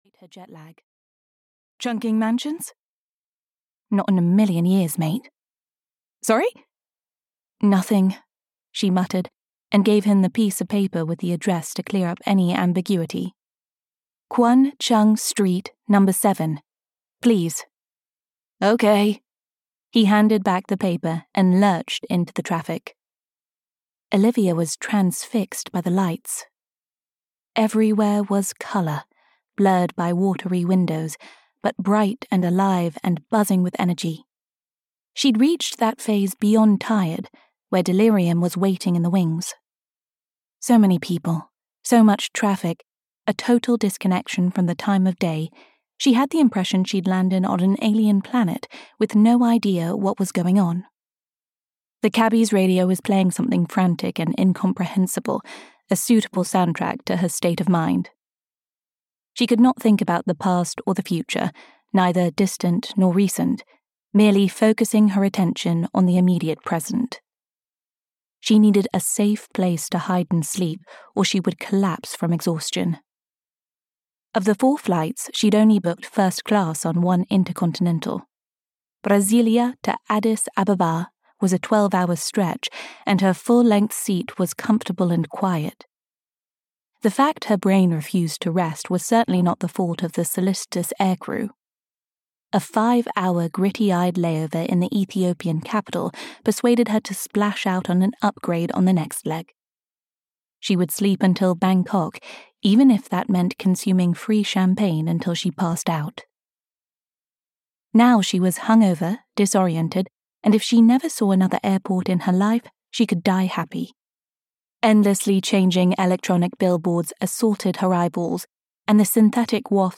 Gold Dragon (EN) audiokniha
Ukázka z knihy
gold-dragon-en-audiokniha